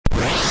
These were all recorded as WAV's and converted to MP3's to save space.
bolt.mp3